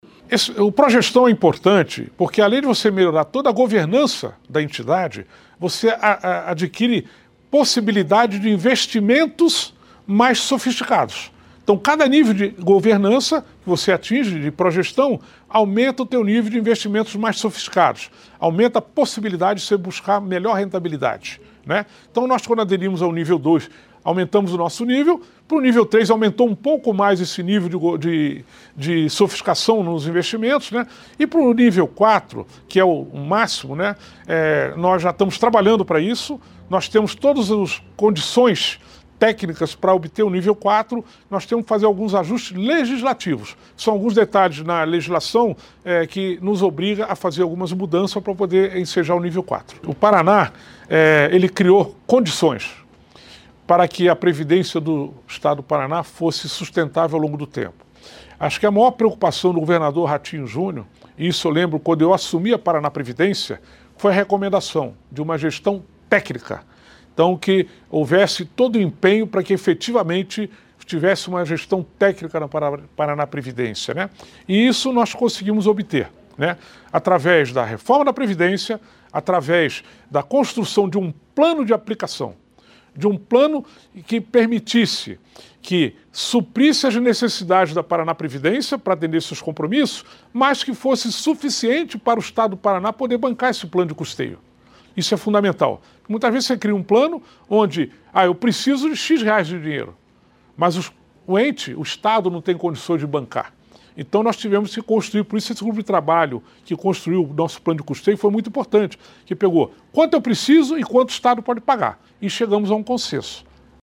Sonora do diretor-presidente da Paranaprevidência, Felipe Vidigal, sobre o Paraná ter o melhor Índice de Situação Previdenciária do País